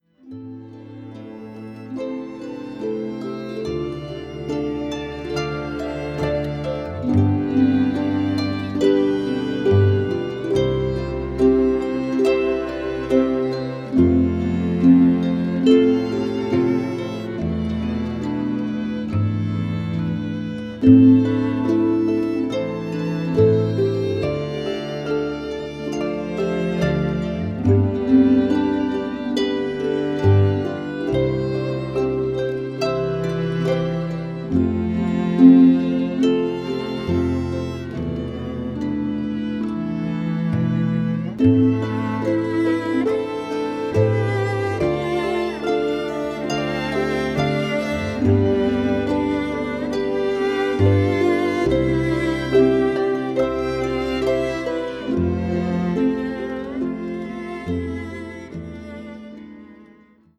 Recorded at the Royal Botanic gardens